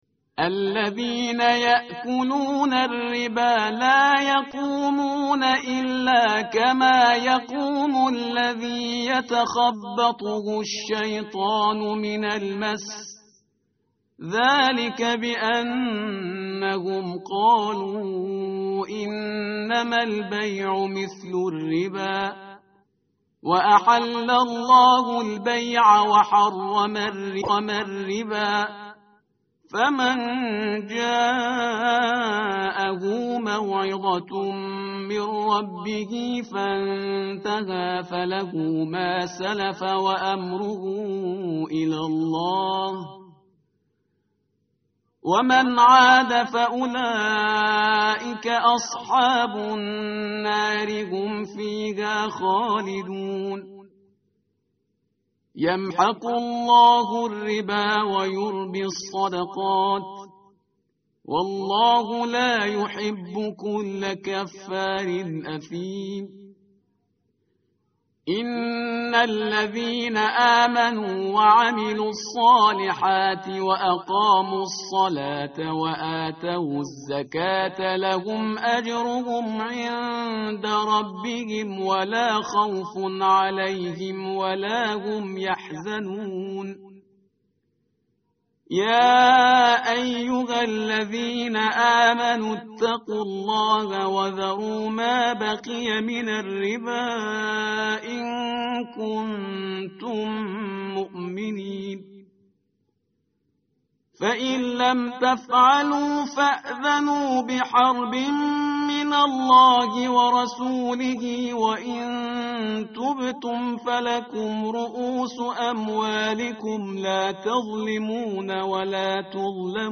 tartil_parhizgar_page_047.mp3